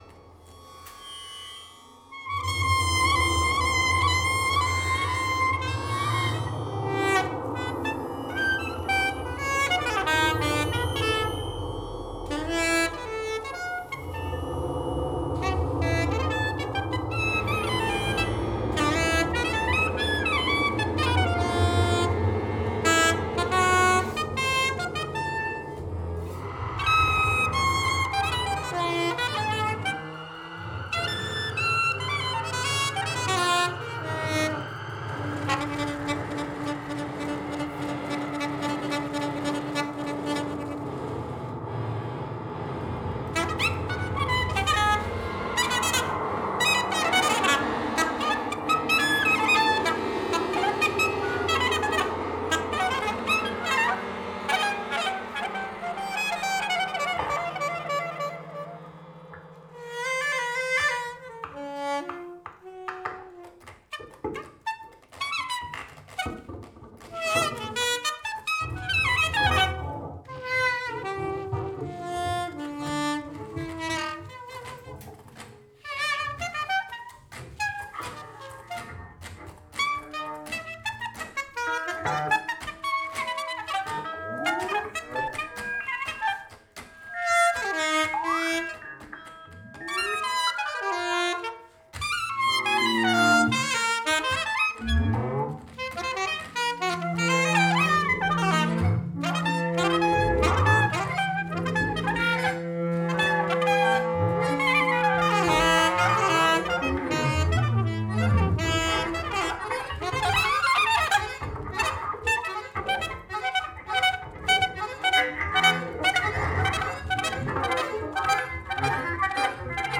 AD HOC TRIO:
accordion
soprano, sopranino sax